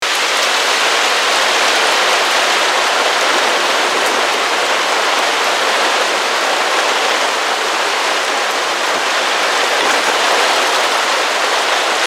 Download Free Hurricane Sound Effects
Hurricane